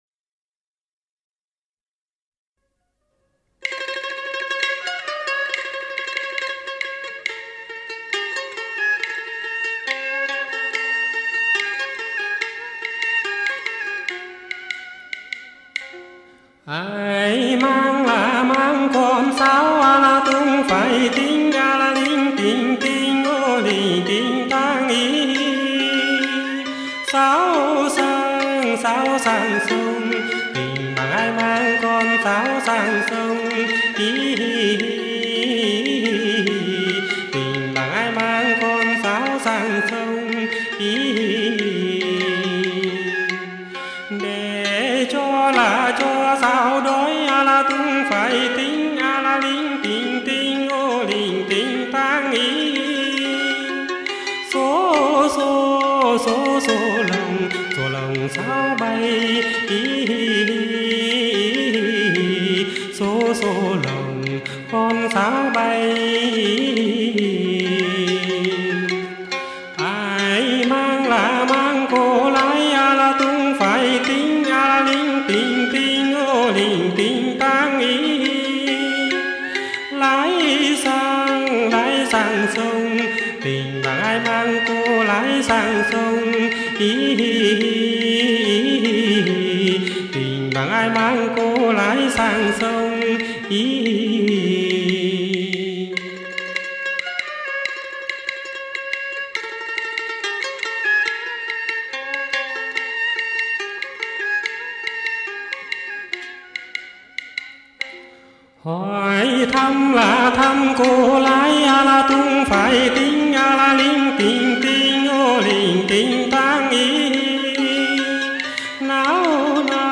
Quan họ lời cổ Lượt nghe